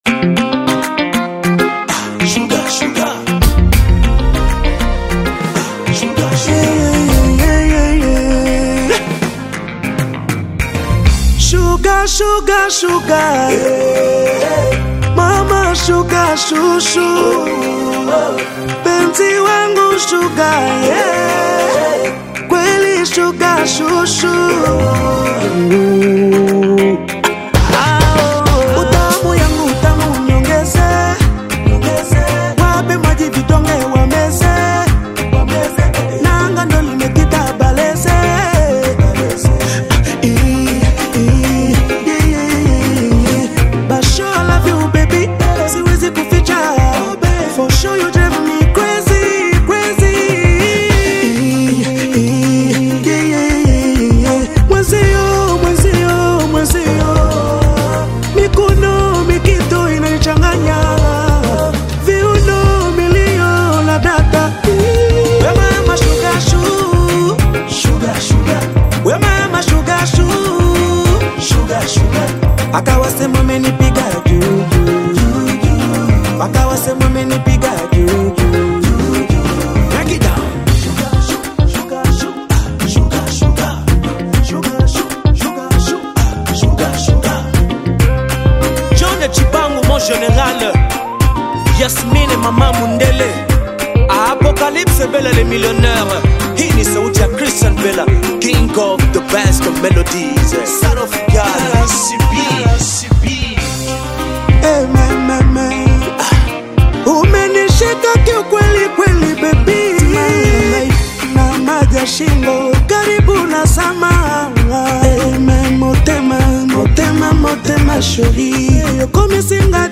smooth Bongo Flava single